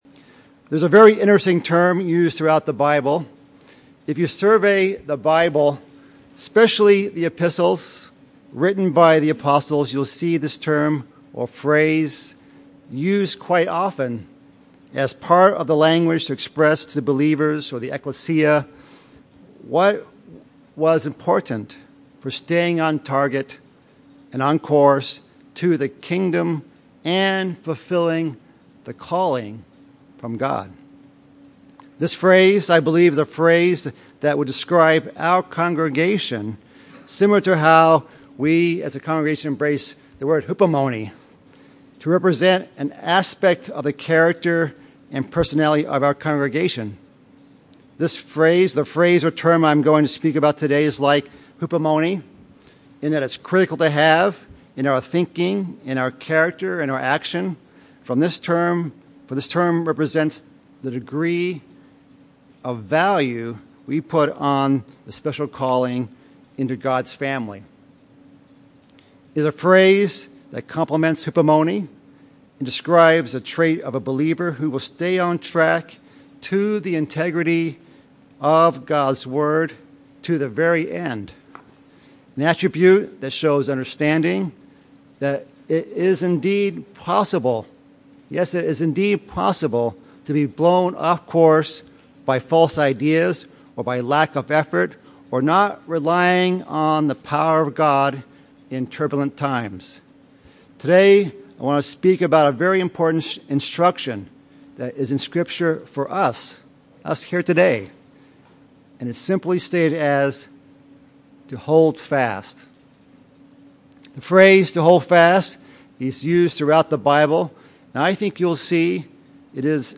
The purpose of this sermon is to review from God’s Word what is KEY and important to HOLD FAST to, so that we as individuals and as a community can be a HOLDING FAST people, striving together for the faith of the gospel.